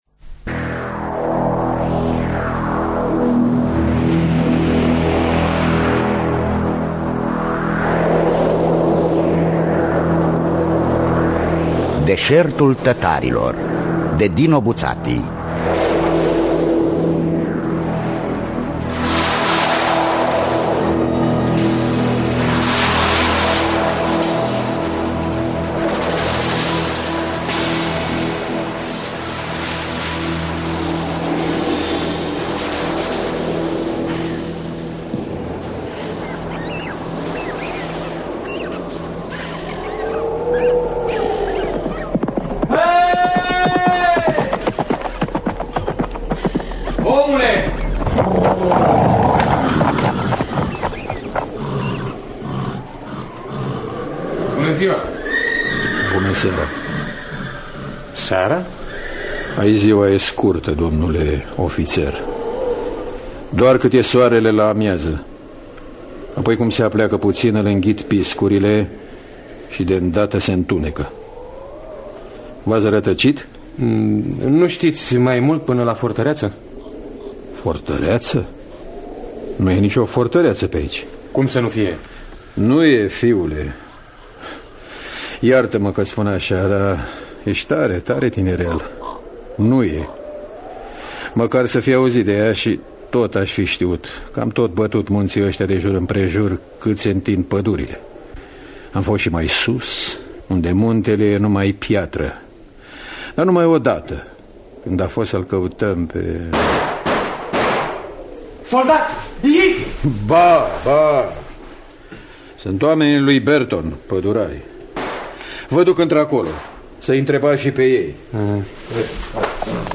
“Deşertul tătarilor” de Dino Buzzati – Teatru Radiofonic Online